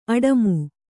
♪ aḍamu